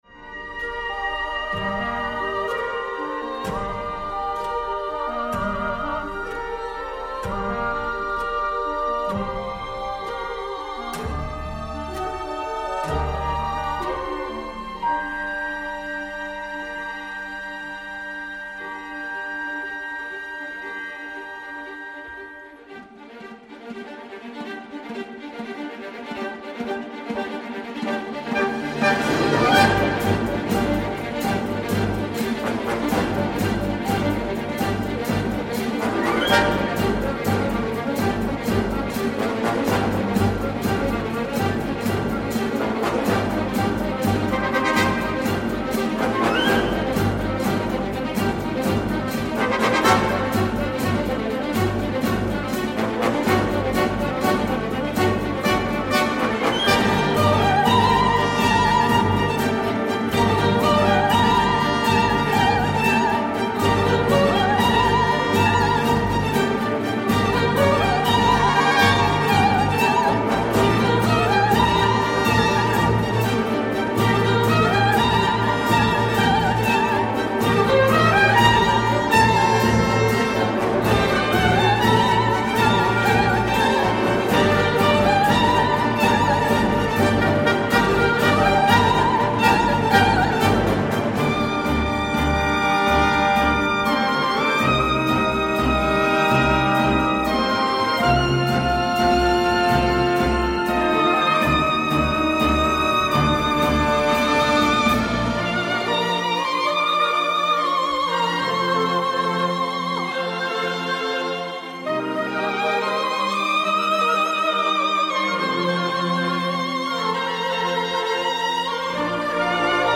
aux émotions puissantes